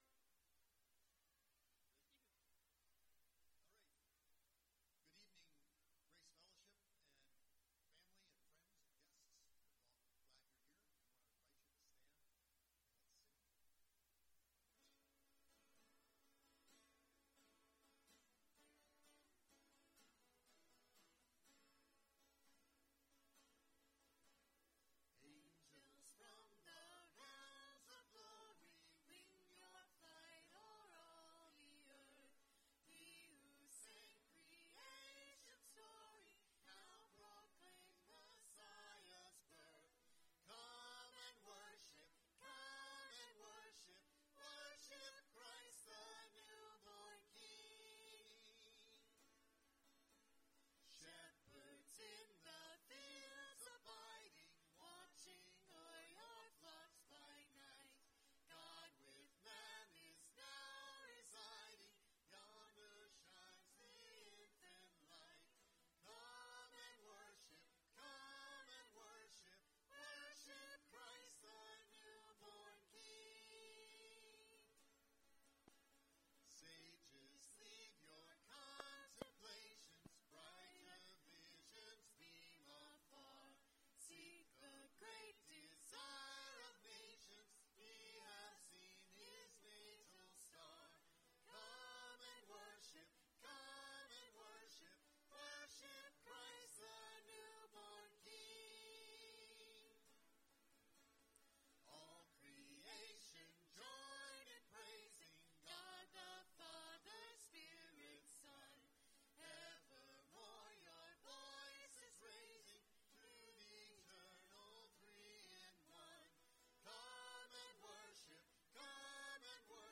Christmas Eve Service | A People For God